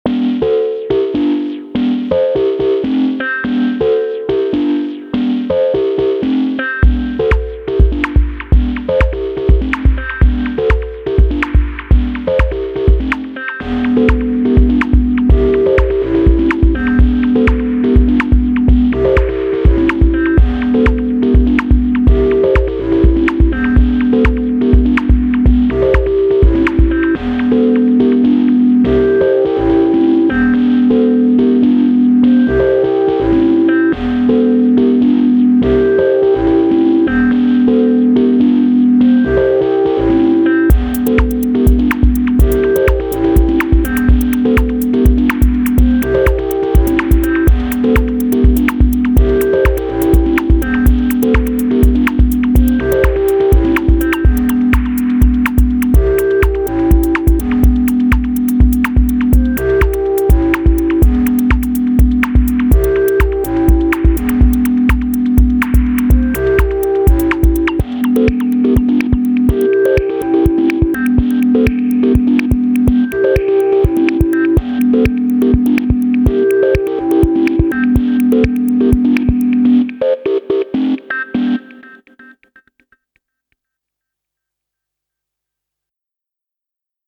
6x SD basic, 2 patterns (struggling a bit with the track levels(mix) on this one, but otherwise also pleasantly surprised by the versatility of SD basic)